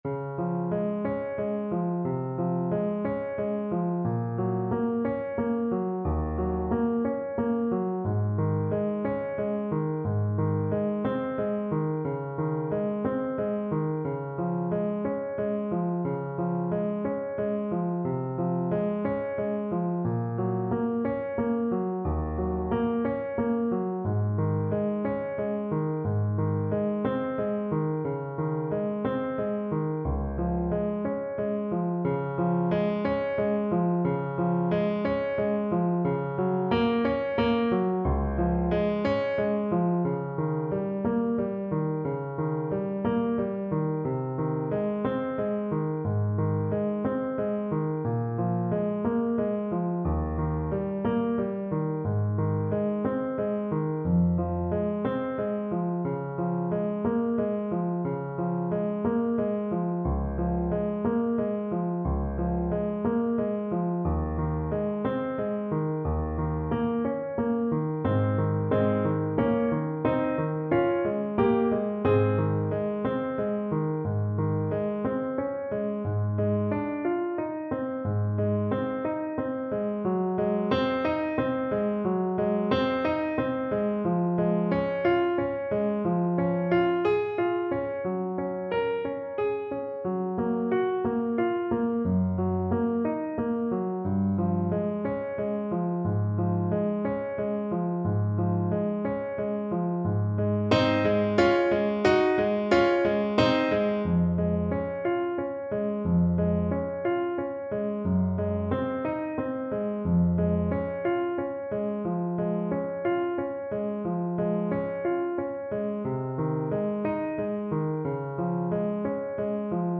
Alto Saxophone
6/8 (View more 6/8 Music)
Andante ma un poco sotenuto . = 40
Classical (View more Classical Saxophone Music)